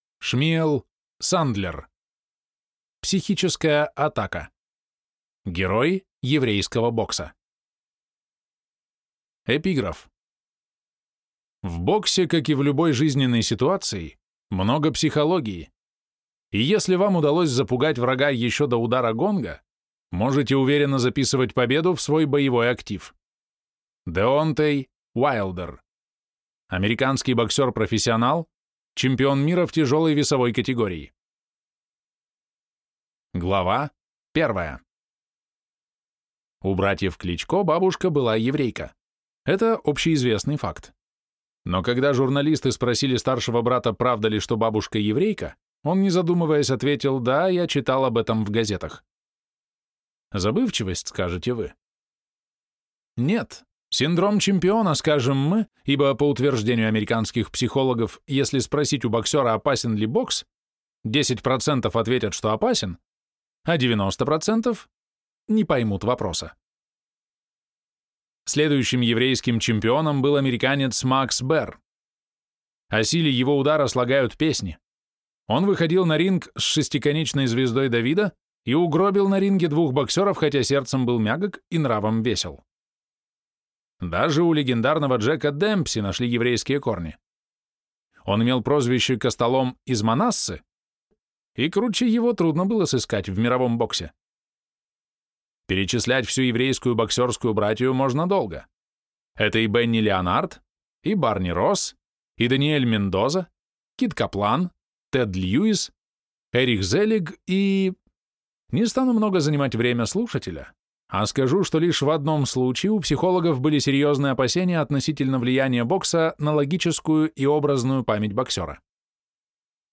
Аудиокнига Психическая атака. Герои еврейского бокса | Библиотека аудиокниг